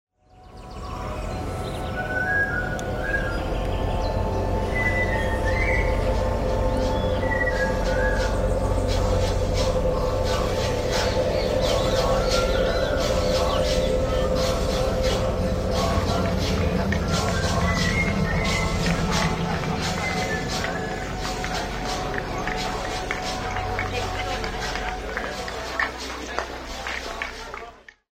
Voices frolm the following cultures:
Buriyart, Arabic, Inuit, Xingu
piano quote